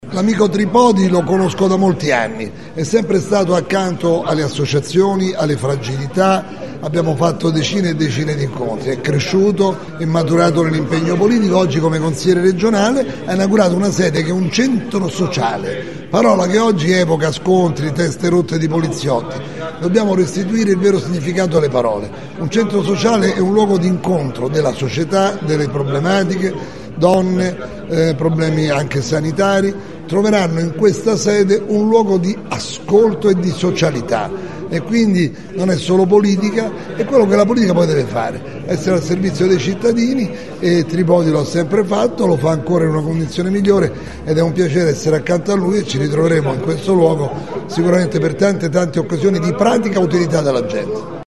Le voci di Gasparri e Regimenti al microfono